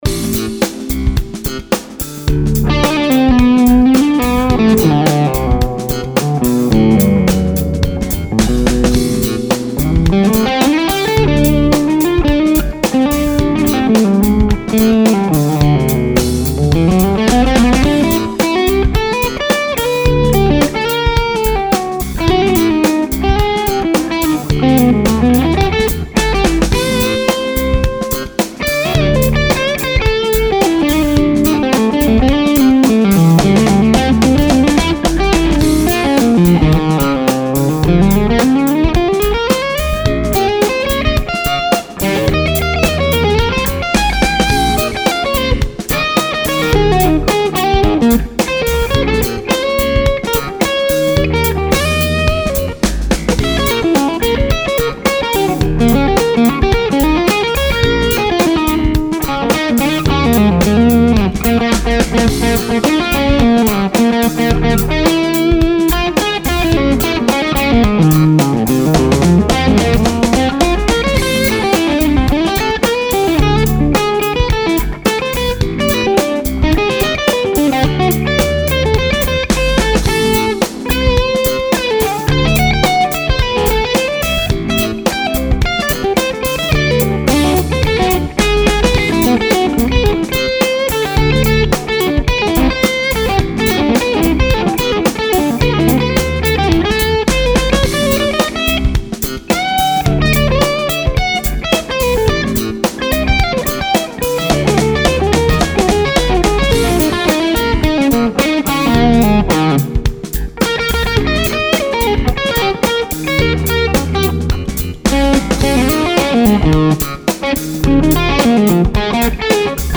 Here is another version using an RFT in V2. Same bass as the first. Much different topend.